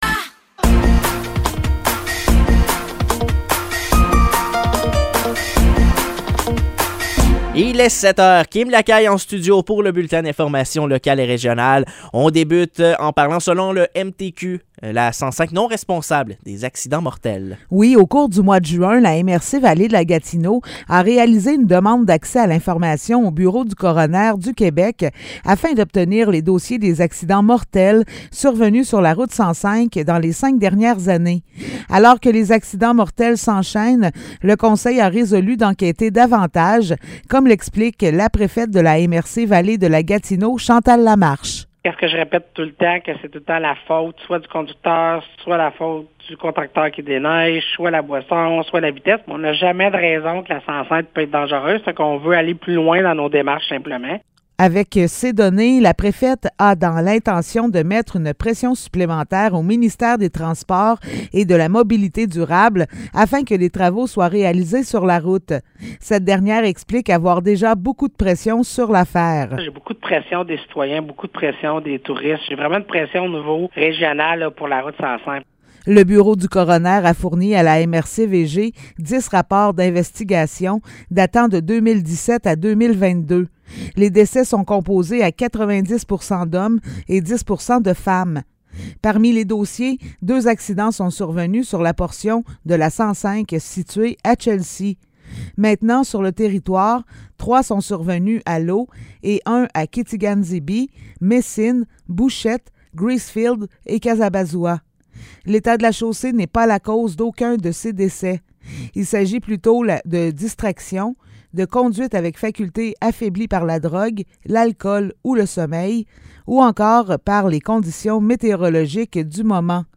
Nouvelles locales - 13 juillet 2023 - 7 h